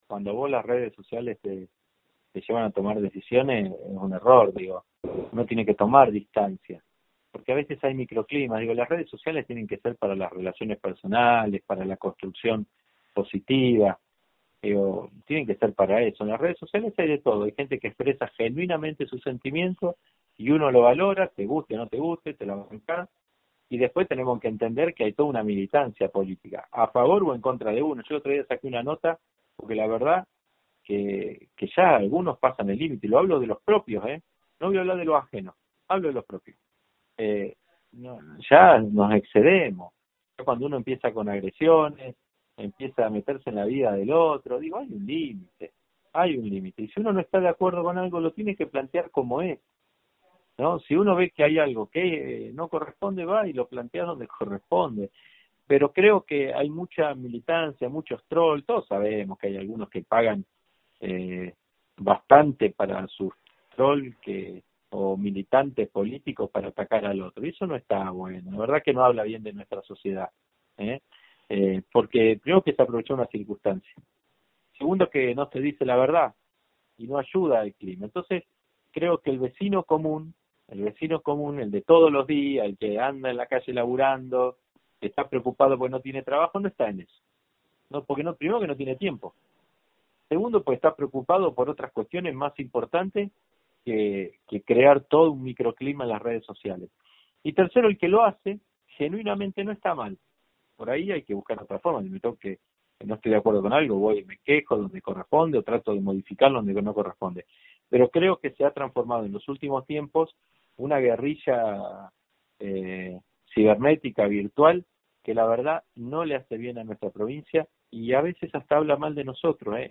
Así lo señaló el Goberandor al analizar lo que ocurre en las redes sociales de Tierra del Fuego. Una nueva parte del diálogo exclusivo con ((La 97)) Radio Fueguina.